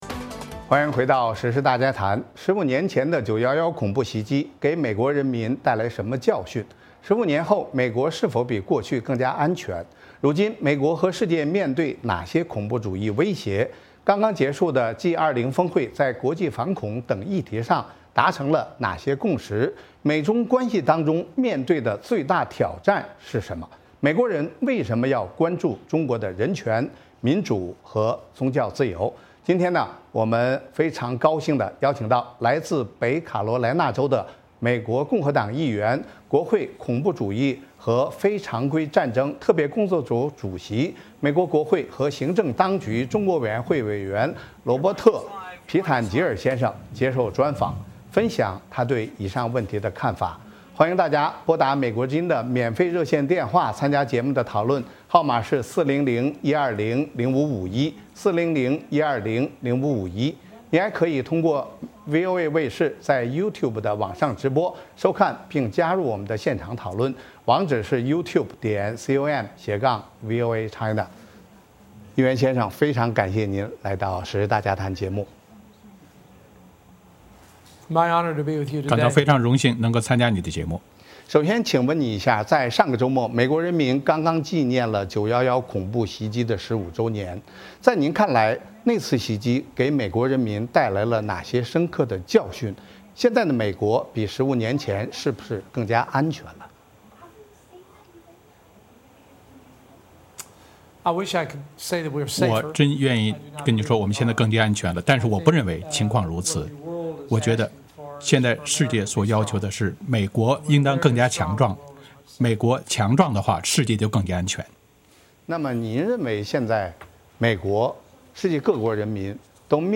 时事大家谈：专访美国国会议员，谈国际反恐和美中关系
今天的时事大家谈邀请到来自北卡罗莱纳州的共和党议员、国会恐怖主义和非常规战争特别工作组主席、美国国会和行政当局中国委员会委员罗伯特·皮坦吉尔(Robert Pittenger)接受专访，分享他对以上问题的看法。